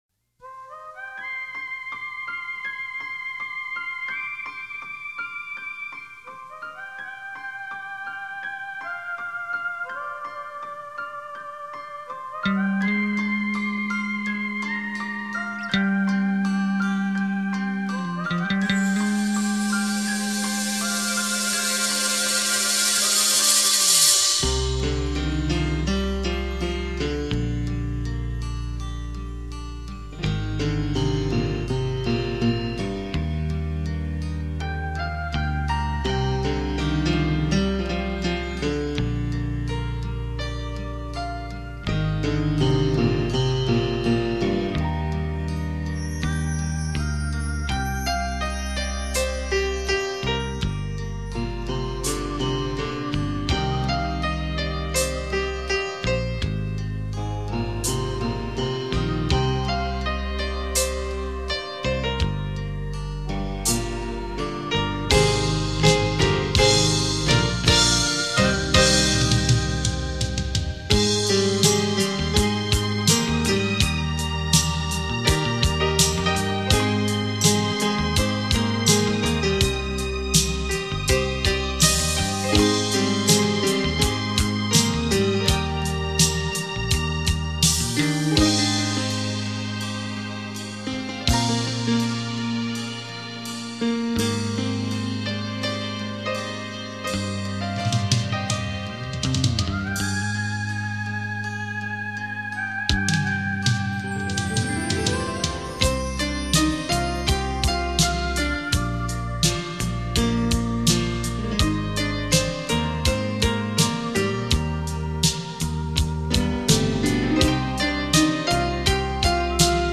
百万双钢琴演奏曲 国语精选
轻盈的钢琴独奏撼动人心 激荡出幽幽淡淡的思乡情愁
优美滑动的旋律 夹杂耐人寻味复杂的思绪于其中
以琴声解码爱情的极致美学 体验钢琴静谧 激情的双面美感